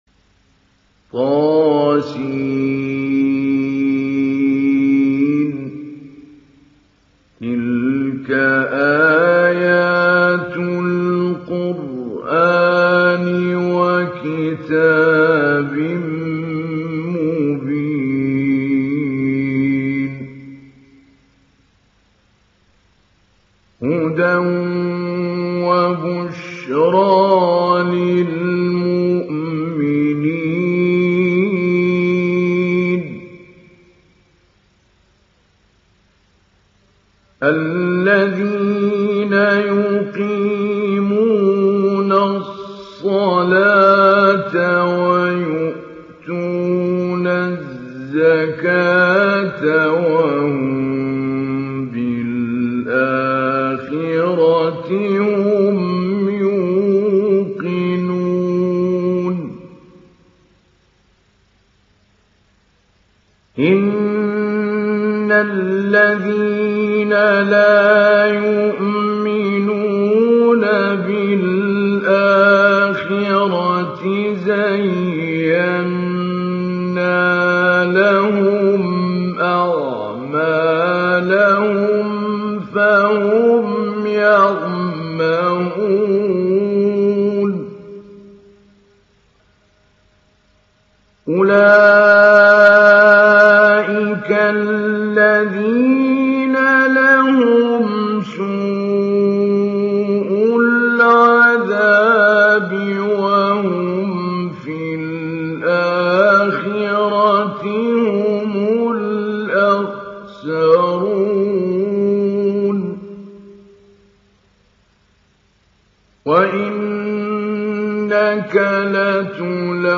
Download Surah An Naml Mahmoud Ali Albanna Mujawwad